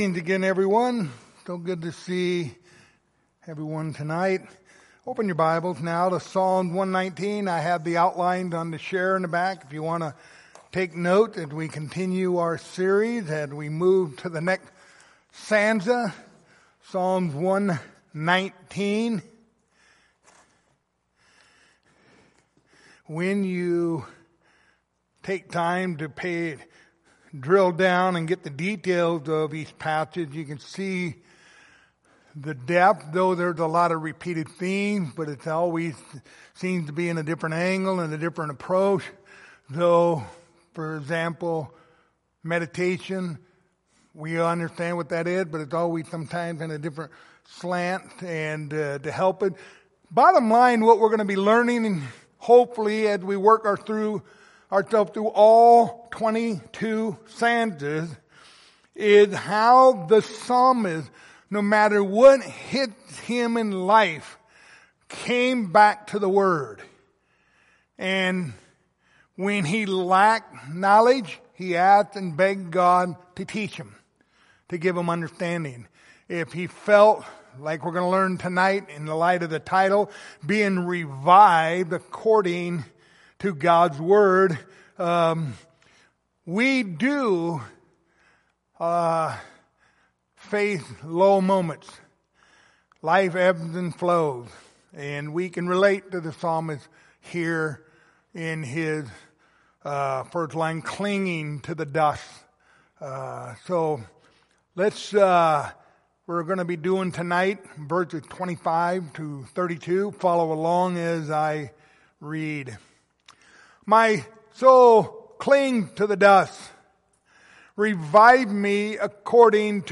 Passage: Psalms 119:25-32 Service Type: Sunday Evening Topics